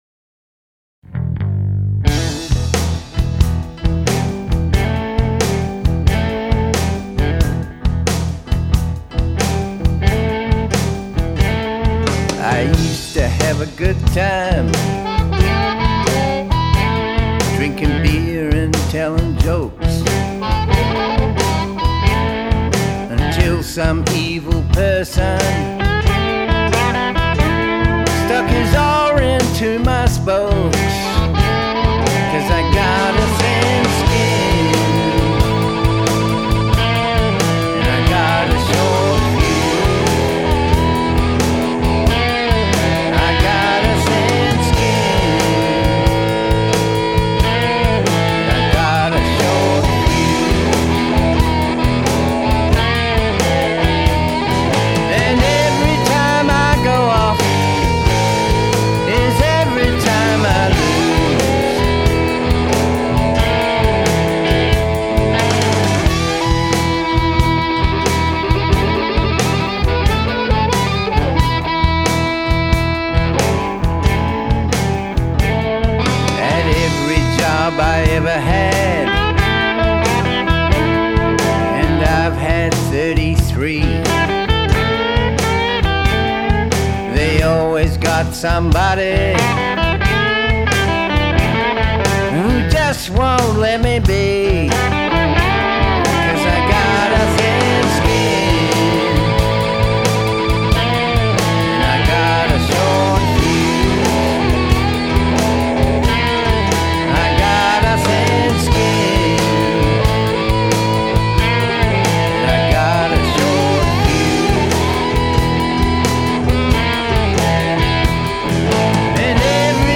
harmonica
piano